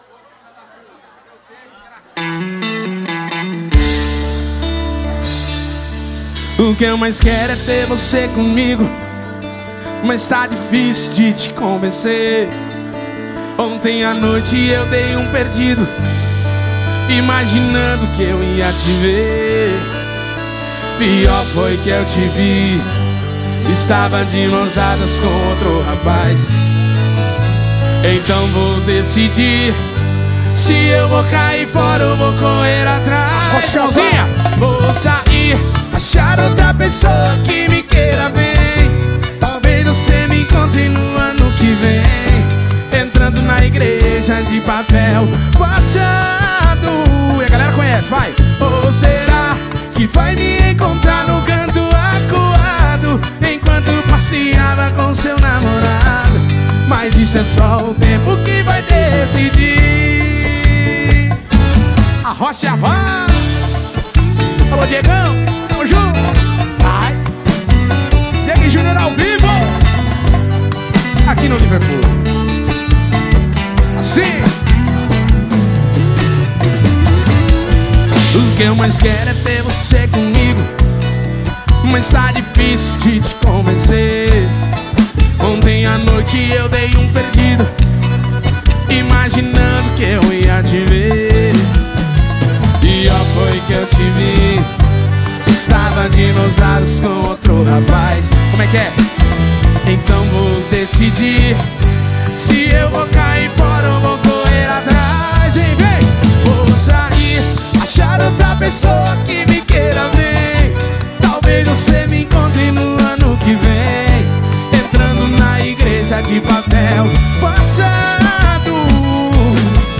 Composição: Cover.